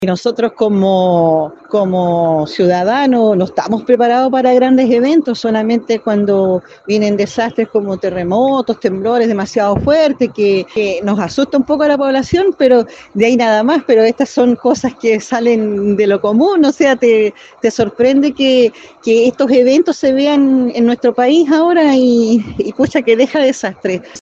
En cuanto a la percepción de los auditores de La Radio, señalan que la comunidad no está preparada para enfrentar este tipo de contingencias.
“Nosotros como ciudadanos no estamos preparados para grandes eventos, solamente cuando vienen desastres como terremotos, temblores fuertes (…)”, comentó la mujer.